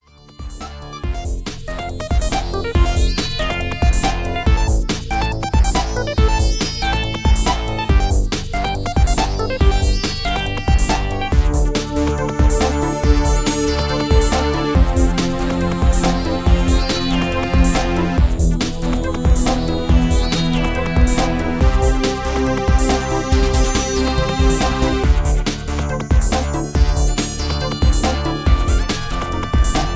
Dramatic Electro Ambient